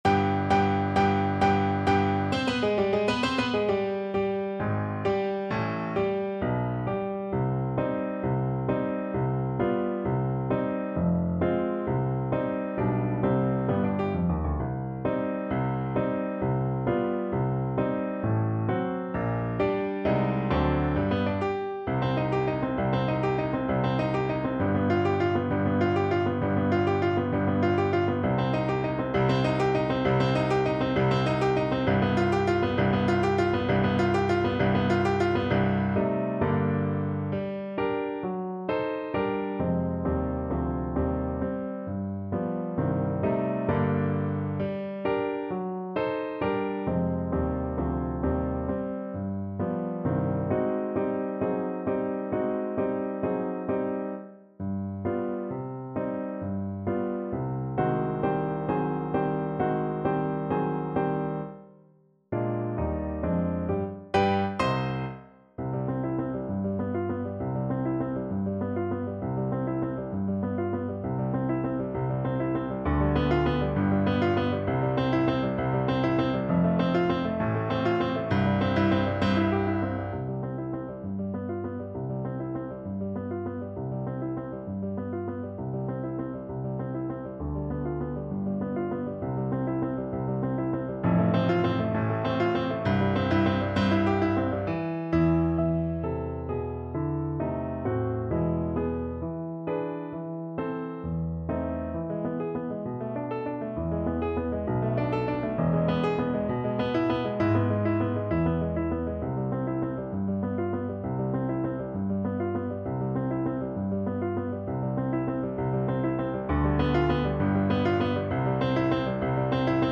= 132 Allegro con spirito (View more music marked Allegro)
6/8 (View more 6/8 Music)
Classical (View more Classical Clarinet Music)